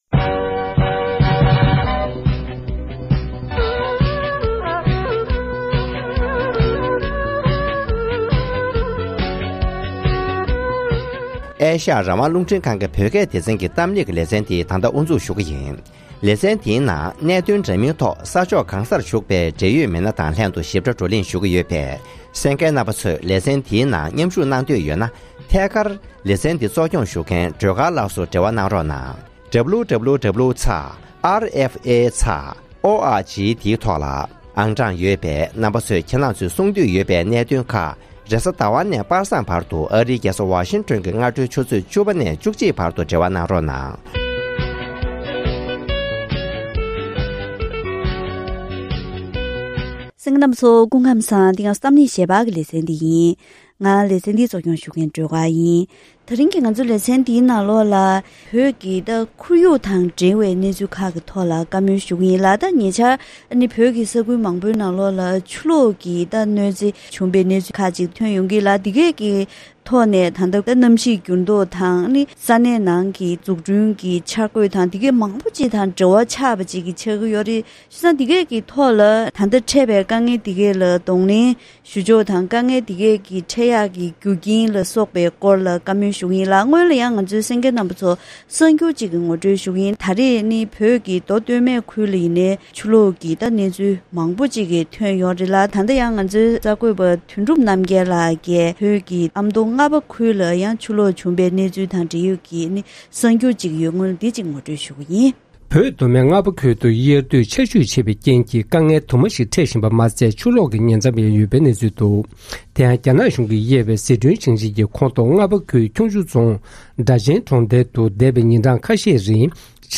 གཏམ་གླེང་